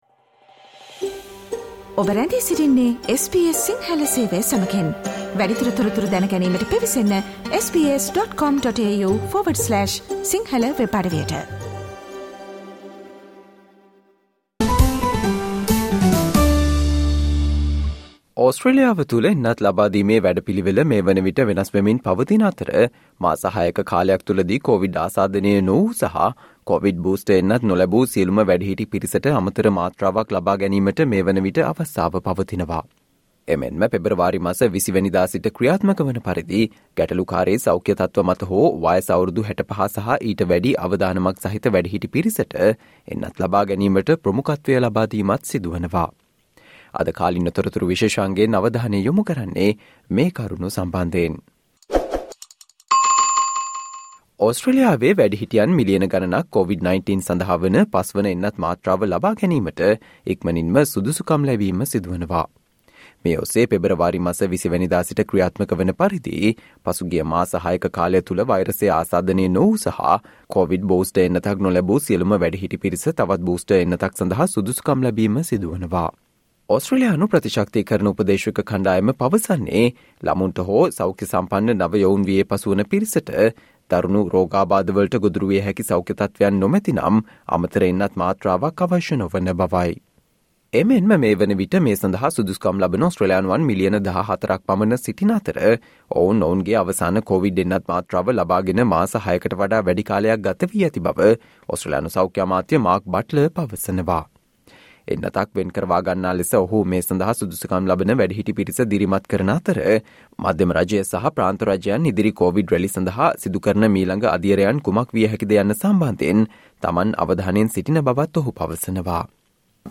Today - 09 February, SBS Sinhala Radio current Affair Feature on Millions of adults will soon be eligible for a fifth dose of a Covid-19 vaccine